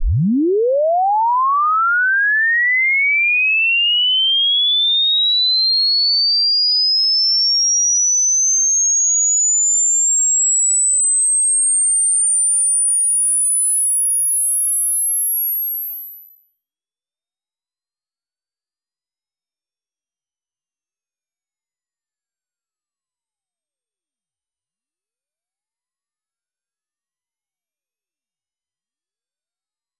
Я сгенерировал в Sound Forge свип-тон от 0 до 30 кГц длительность 30 сек. Т.е. 1-я секунда соответствует 1 кГц, 10-я соответсвует 10 кГц, 20-я соответсвует 20 кГц, а 30-я соответсвует 30 кГц. В формате wav (1536kbps): Вложение 455870 И в формате mp3 (320kbps): Вложение 455868 Простая проверка своего слуха и своей акустики.